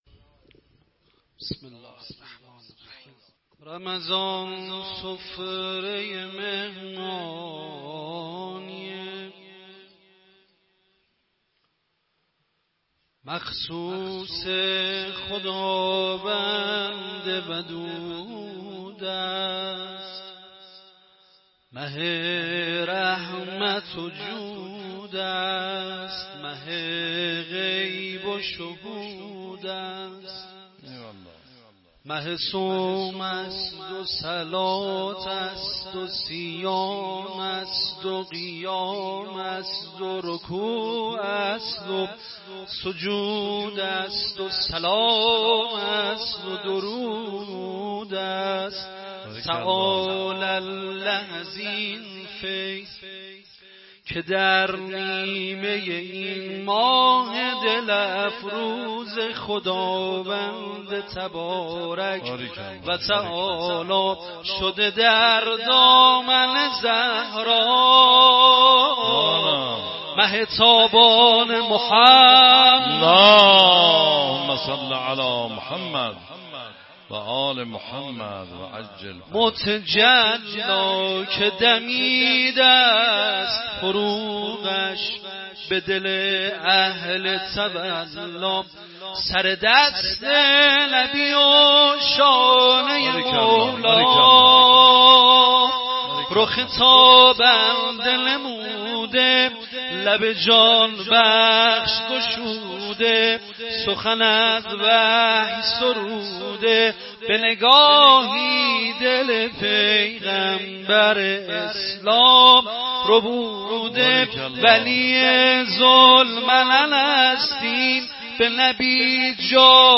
بحرطویل خوانی
در شب ۱۵رمضان ۹۶ در مجمع الذاكرين حسینی تهران